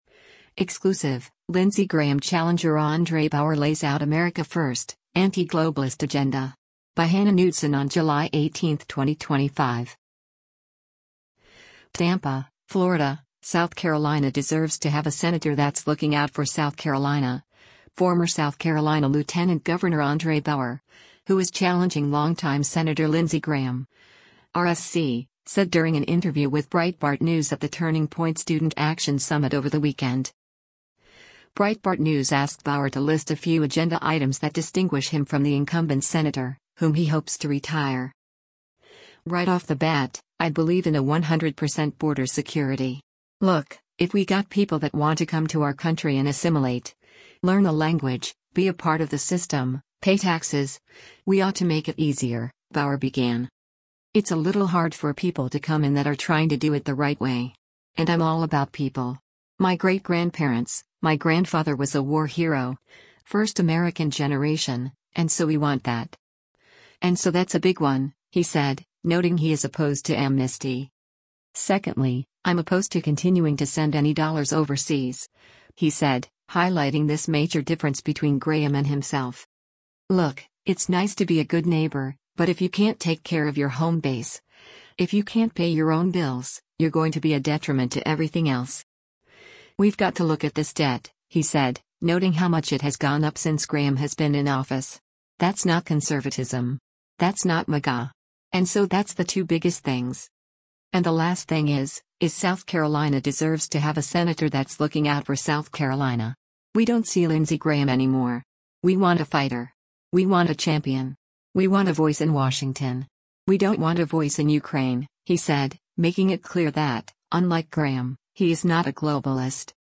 TAMPA, Florida –“South Carolina deserves to have a senator that’s looking out for South Carolina,” former South Carolina Lt. Gov. André Bauer, who is challenging longtime Sen. Lindsey Graham (R-SC), said during an interview with Breitbart News at the Turning Point Student Action Summit over the weekend.